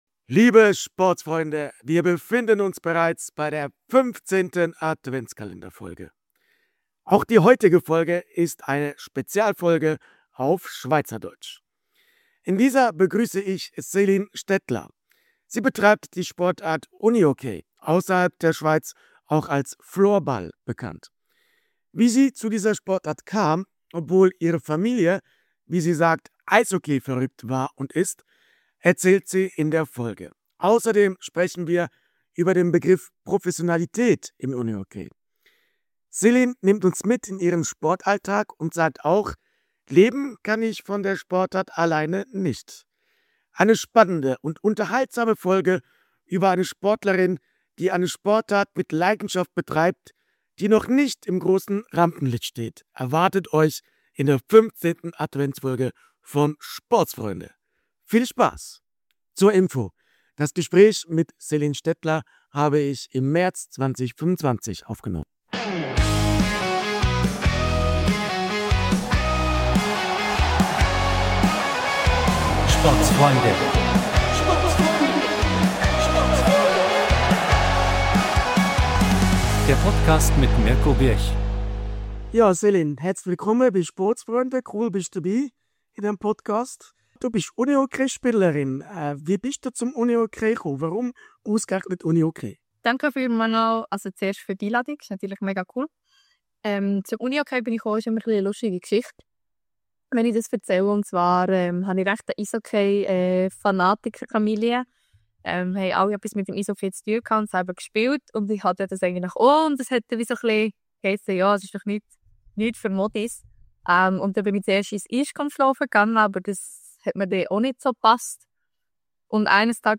Spezialfolge auf Schweizerdeutsch! ~ Mixed-Sport Podcast